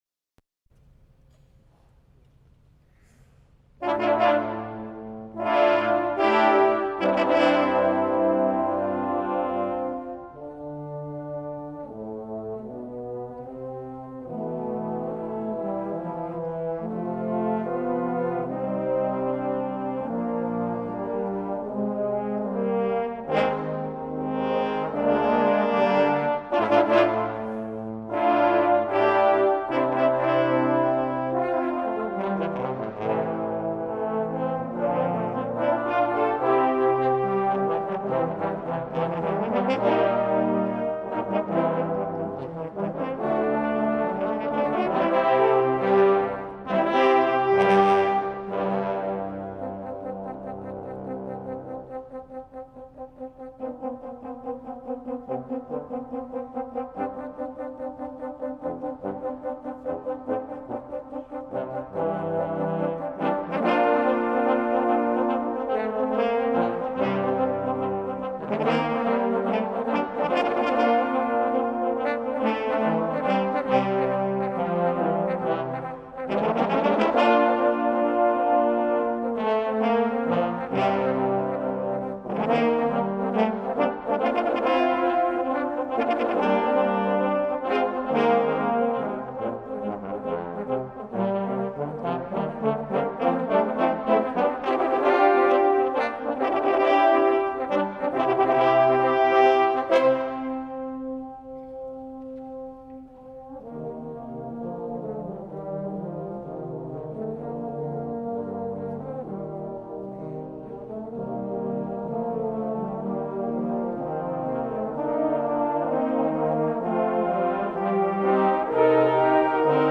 For Trombone Quartet